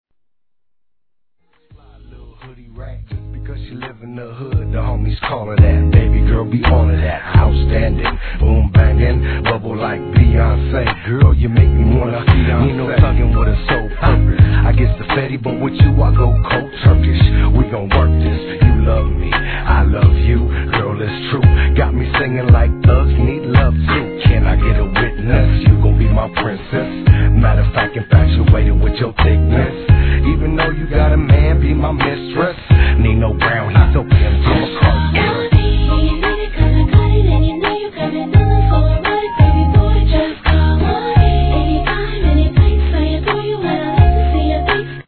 HIP HOP/R&B
可愛らしい女性のヴォーカルがフックで際立つメロウチューン